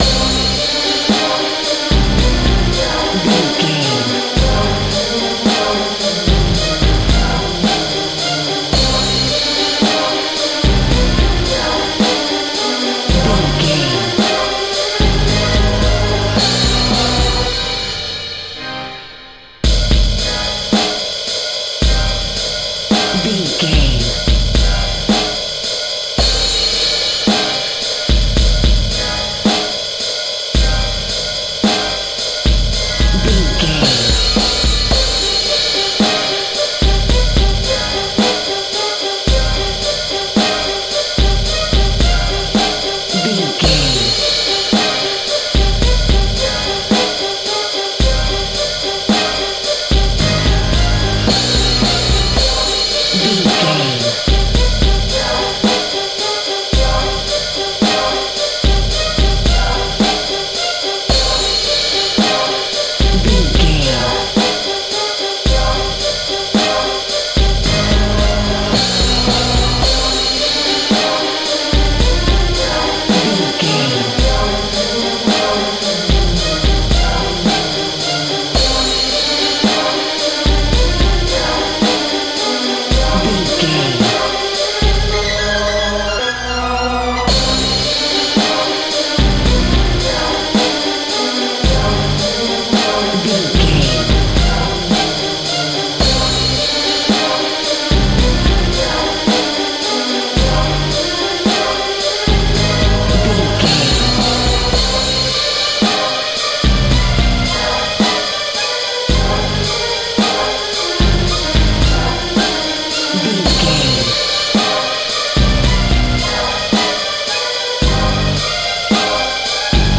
Aeolian/Minor
scary
tension
ominous
dark
suspense
eerie
synthesiser
drums
percussion
organ
strings
pads